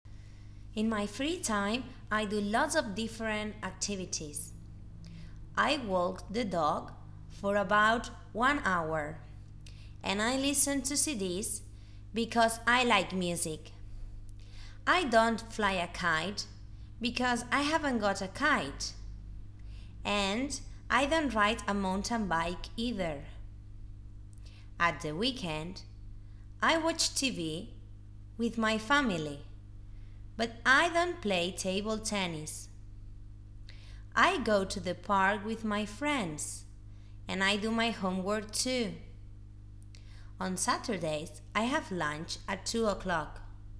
Dictation Unit 1